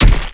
axe_wood.wav